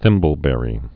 (thĭmbəl-bĕrē)